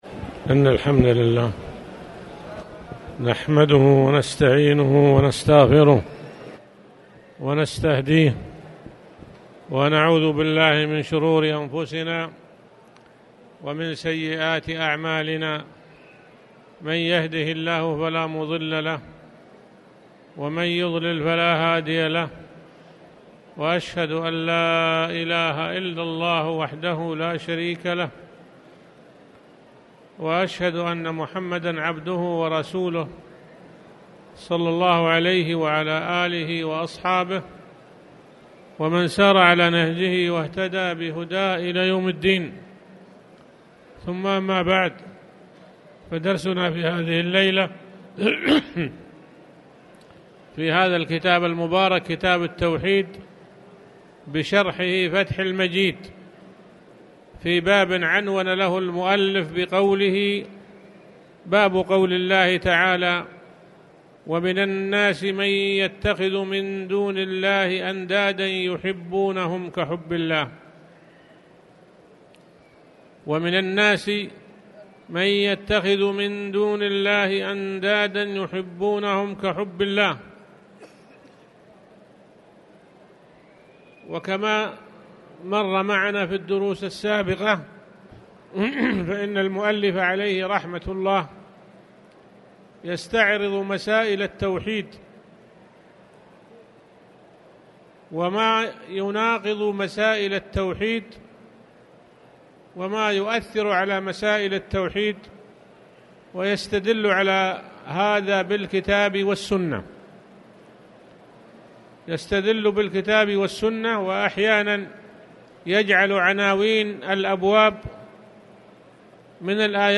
تاريخ النشر ١٧ شوال ١٤٣٨ هـ المكان: المسجد الحرام الشيخ